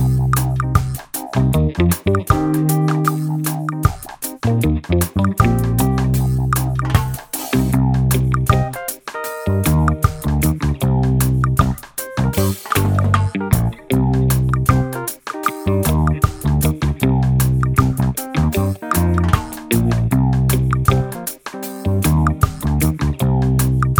minus wahwah guitar no Backing Vocals Reggae 3:16 Buy £1.50